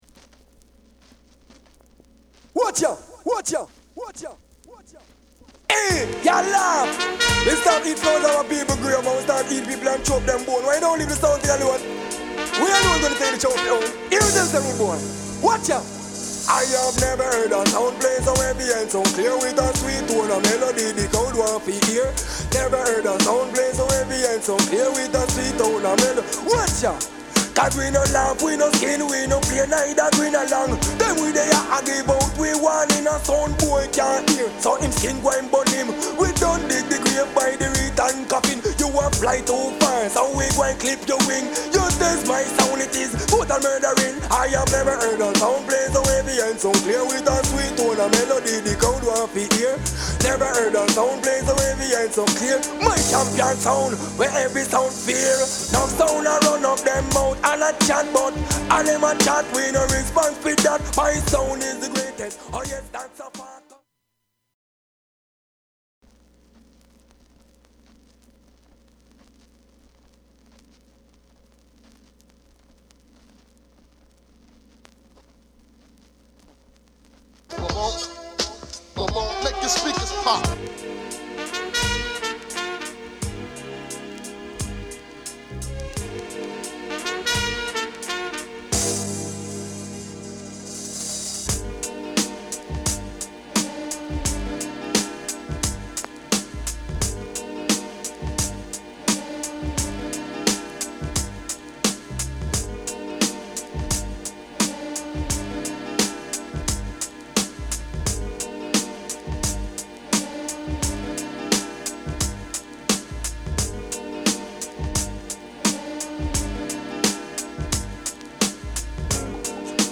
RAGGA HIP HOP / REGGAE / DANCEHALL